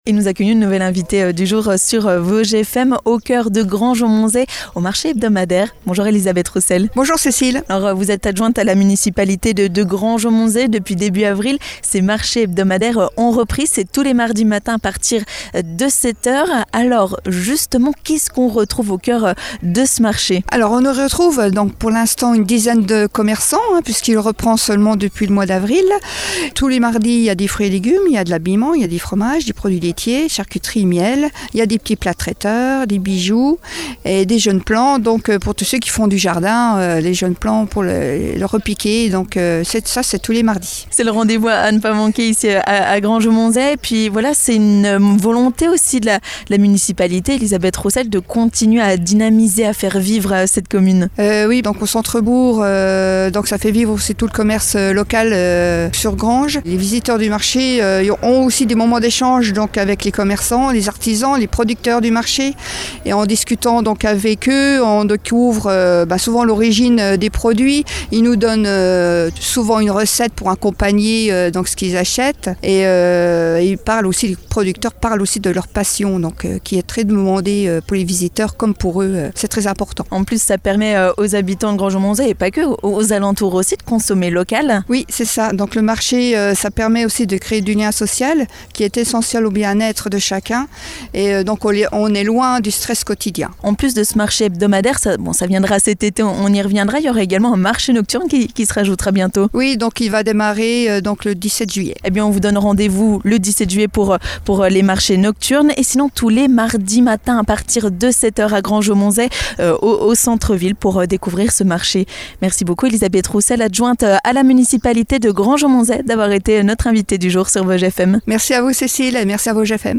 3. L'invité du jour
On en parle avec notre Invitée du Jour, Elisabeth Roussel, adjointe à la municipalité de Granges-Aumontzey.